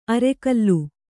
♪ arekallu